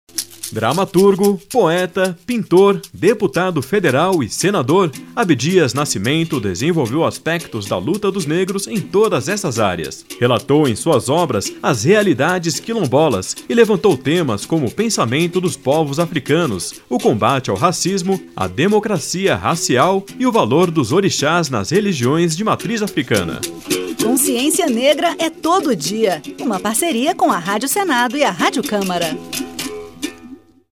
A Rádio Senado e a Rádio Câmara lançam uma série de cinco spots que homenageiam personalidades negras que marcaram a história do Brasil, como Abdias Nascimento, Carolina Maria de Jesus, Dragão do Mar, Maria Firmina dos Reis e Luiz Gama.